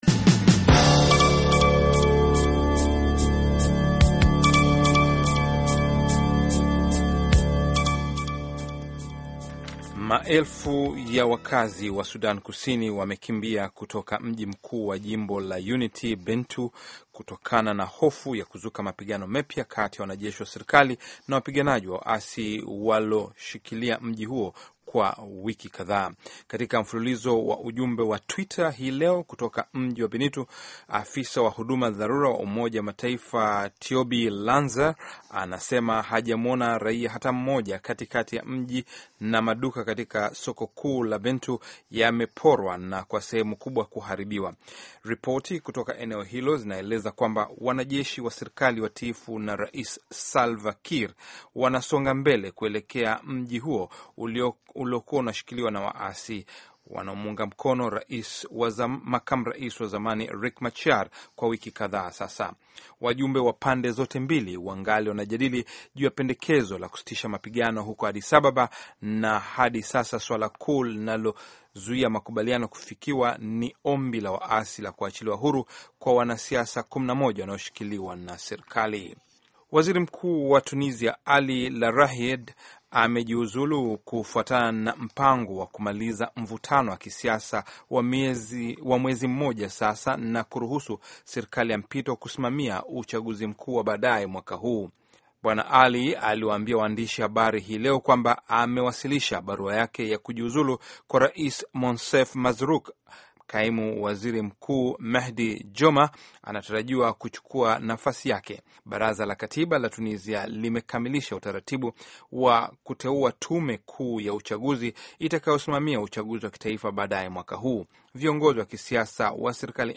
Taarifa ya Habari VOA Swahili - 6:18